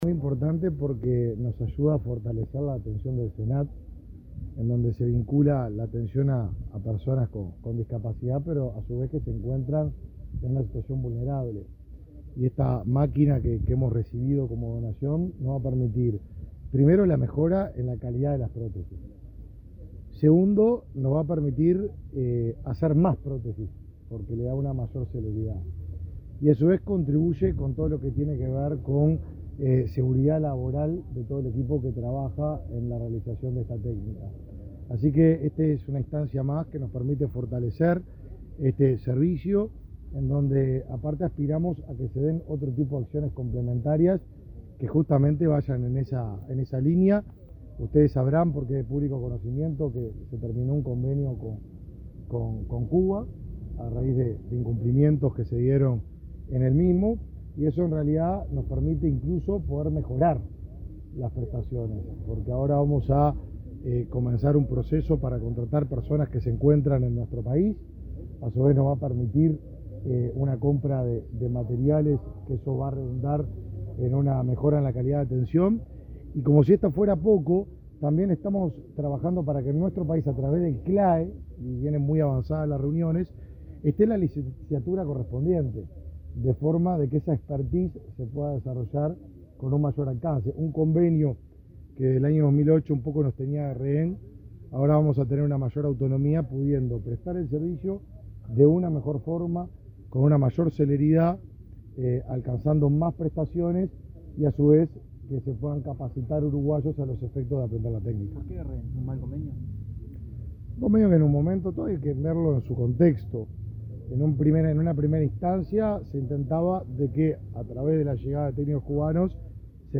Declaraciones de autoridades del Mides
El ministro Martín Lema y la directora de Discapacidad del MIDES, Karen Sass, dialogaron con la prensa sobre la donación de la empresa Ottobock.